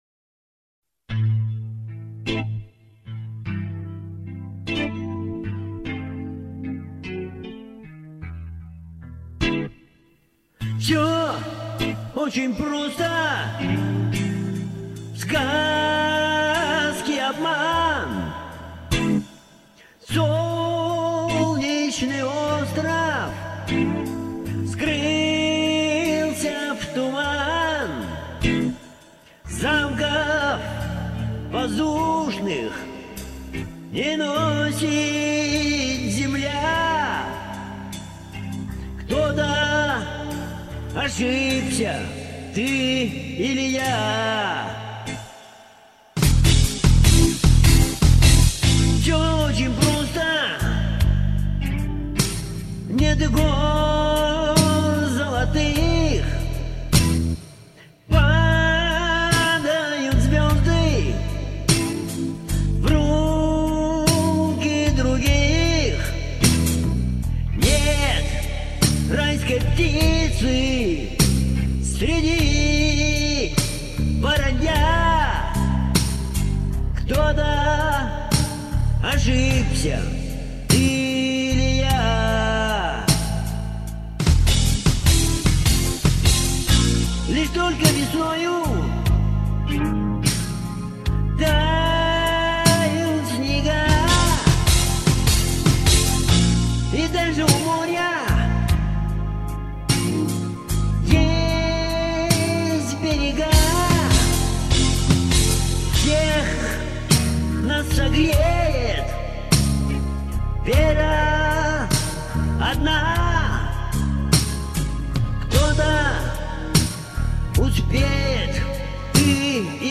Два исполнения совсем не похожи друг на друга!!!!